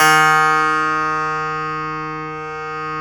53s-pno06-D1.aif